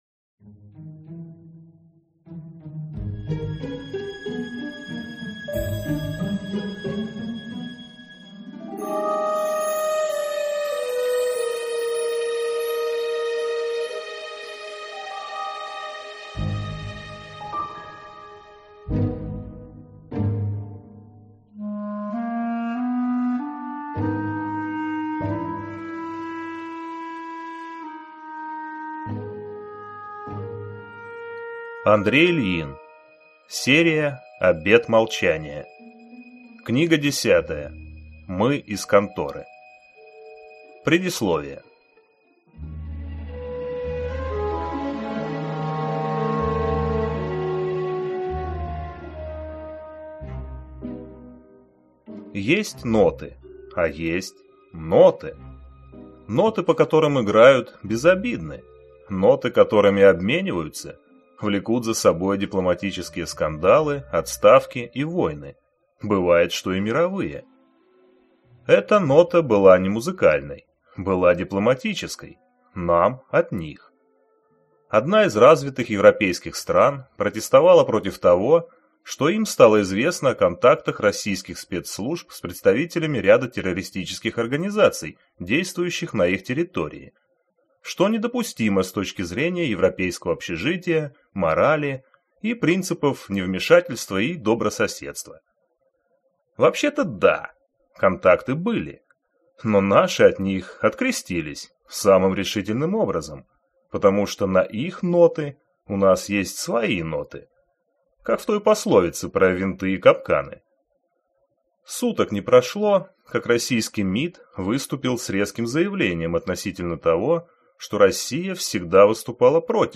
Аудиокнига Мы из Конторы | Библиотека аудиокниг